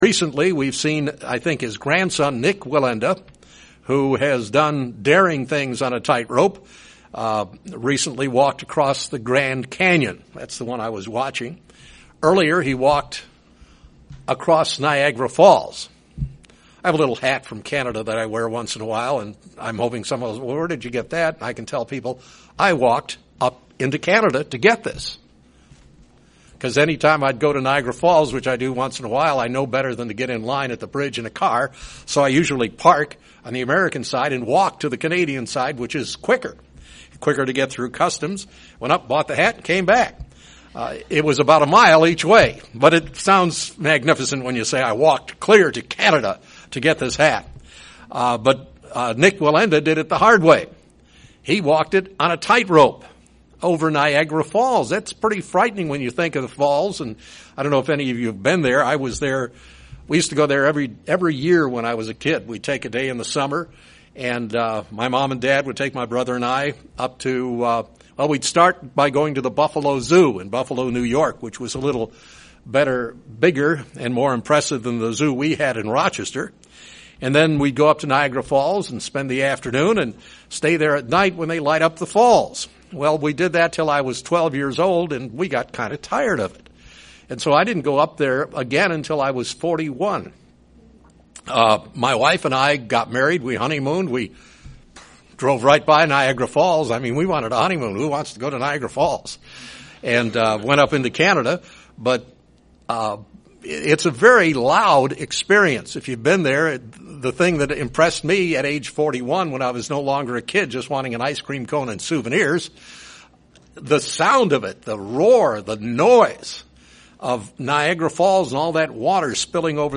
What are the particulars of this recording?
Given in Springfield, MO